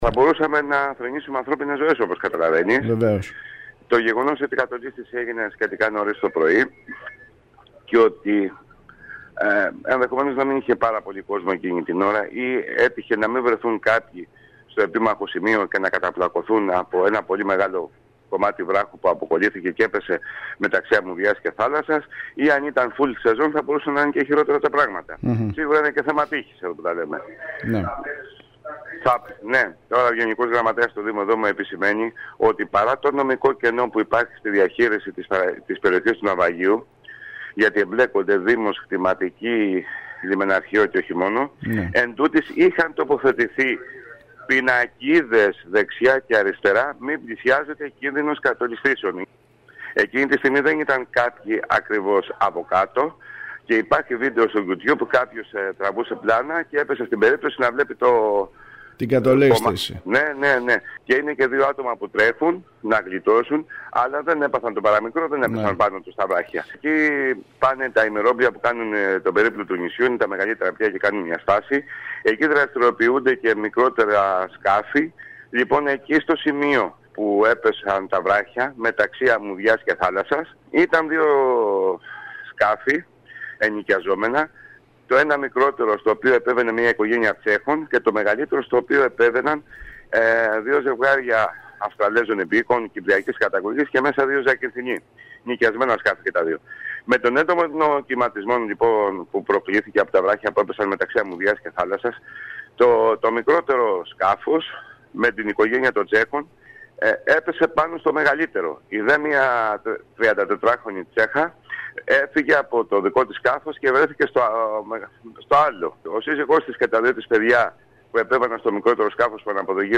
περιέγραψε στο σταθμό μας τις συνθήκες κάτω από τις οποίες συνέβη το περιστατικό, το οποίο ευτυχώς δεν κατέληξε στο βαρύ τραυματισμό ή τον θάνατο κάποιου.